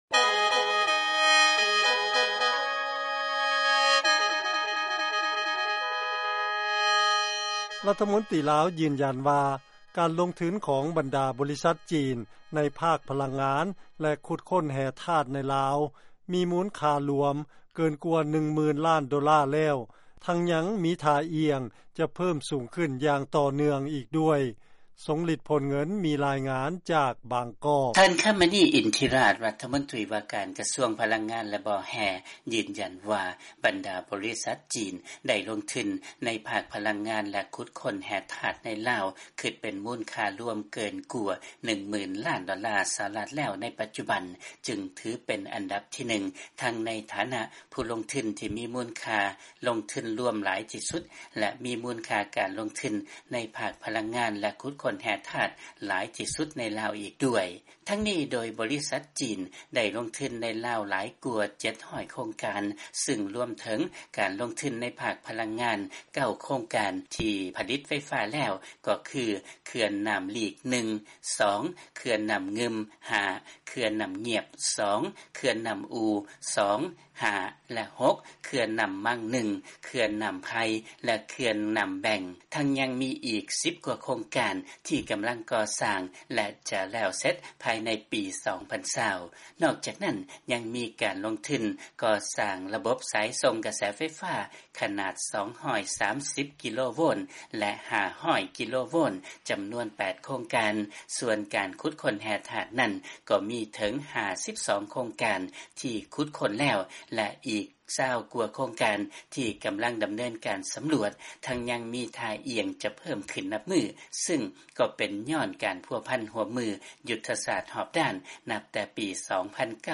ເຊີນຟັງລາຍງານ ບັນດາບໍລິສັດຈາກຈີນ ໄດ້ລົງທຶນ ຢູ່ໃນລາວ ເກີນກວ່າ 10,000 ລ້ານໂດລາ ແລ້ວ